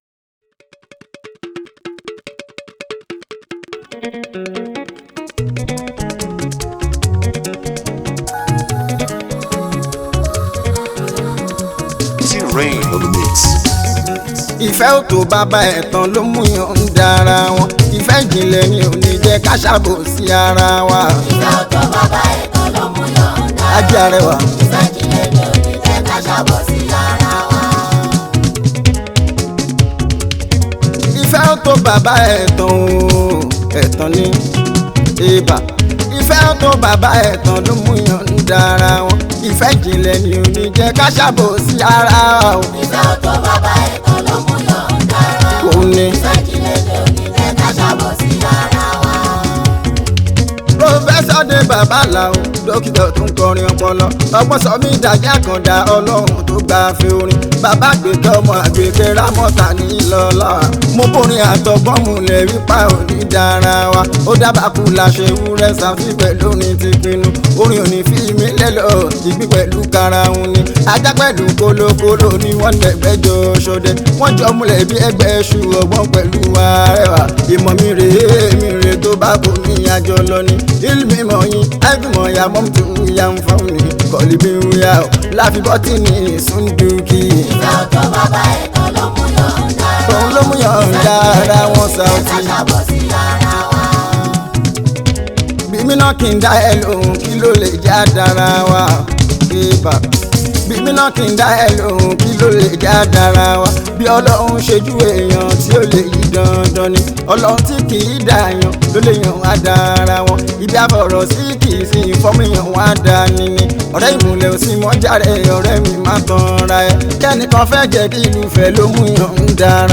Prolific award winning Fuji Music singer
Yoruba Fuji song